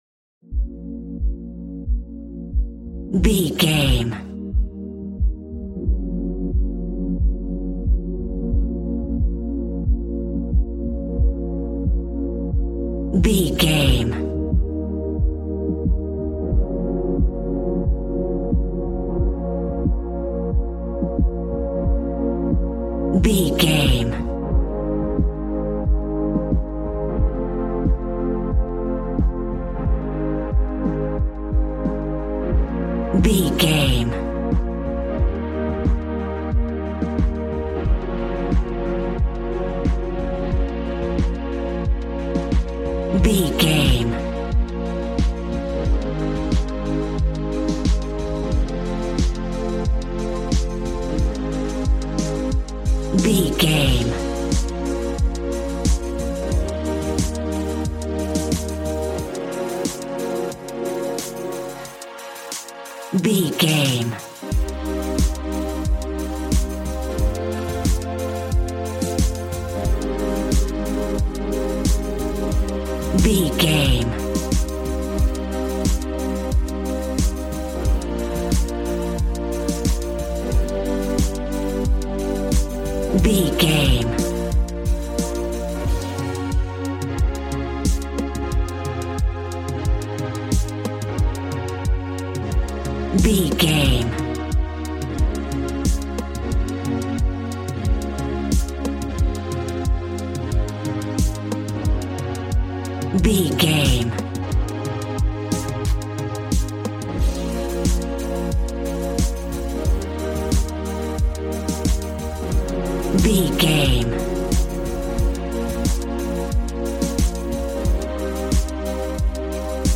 Aeolian/Minor
B♭
Fast
uplifting
lively
groovy
synthesiser
drums